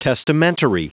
Prononciation du mot testamentary en anglais (fichier audio)
Prononciation du mot : testamentary